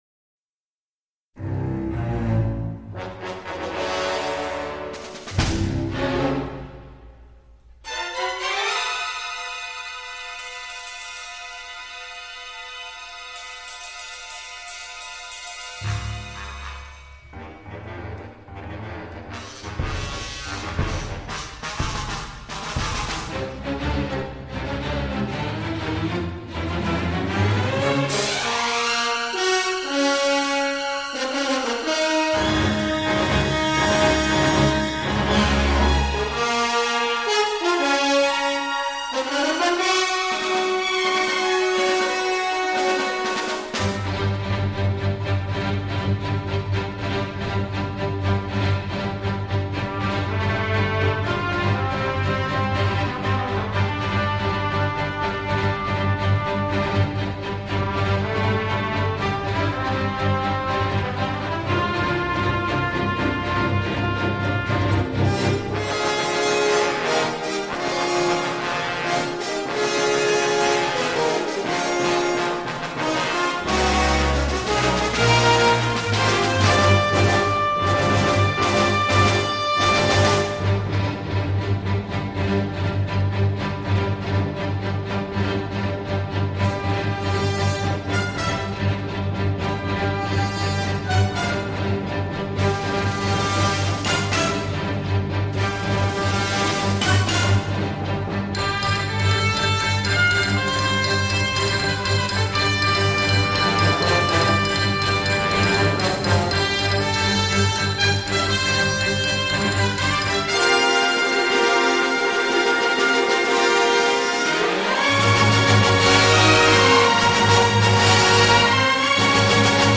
Ample et élégiaque
agressif, strident parfois